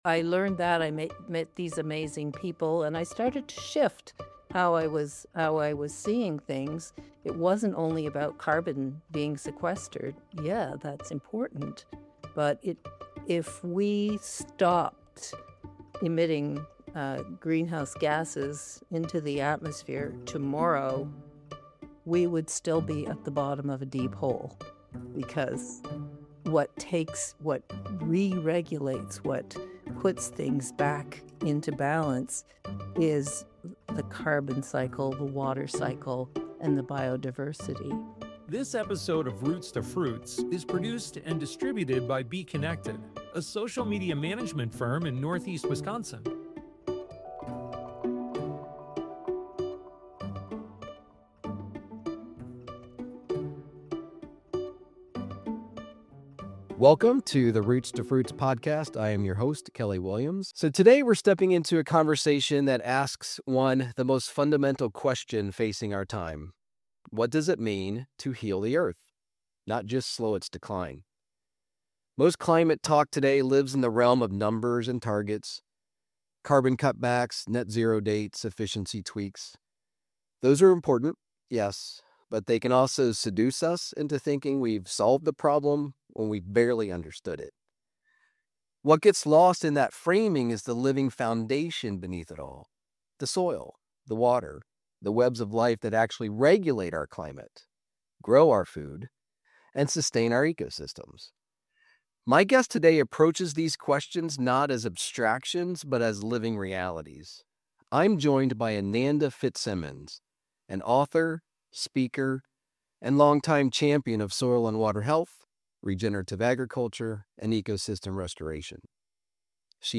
Listen to the full conversation to explore what true regeneration could look like!